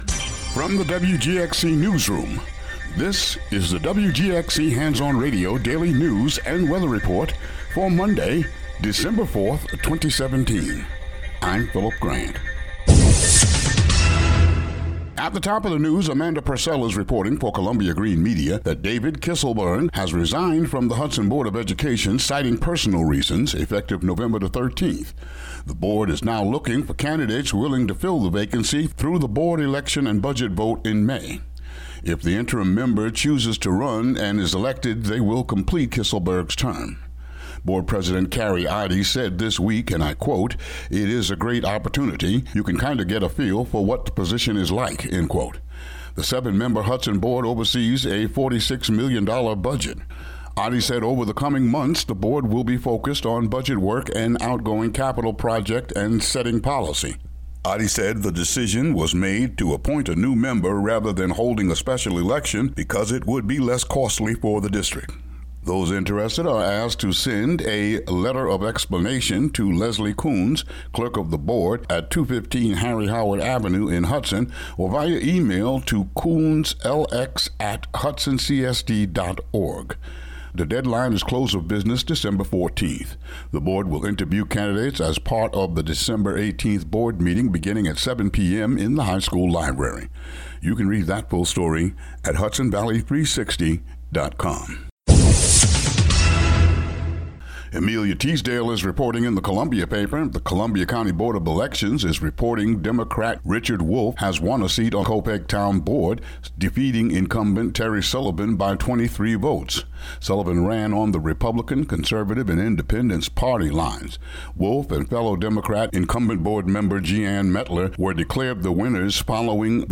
Daily local news for Mon., Dec. 4.